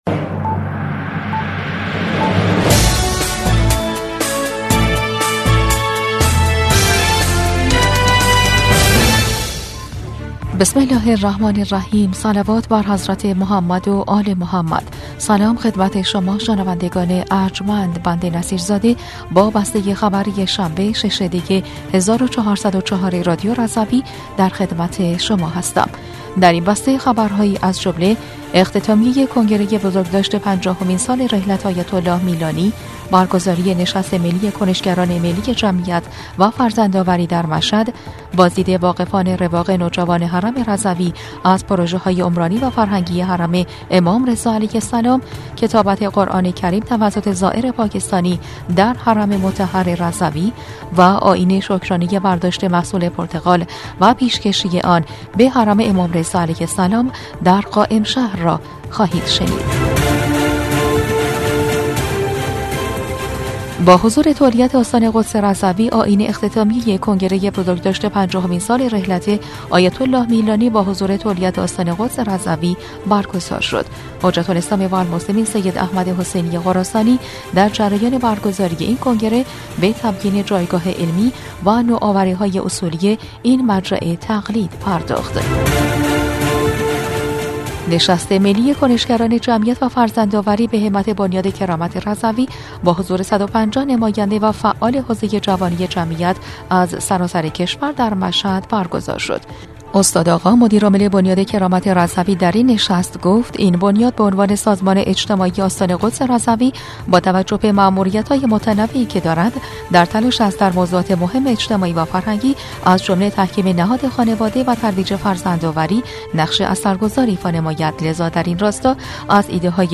بسته خبری ۶ دی ۱۴۰۴ رادیو رضوی؛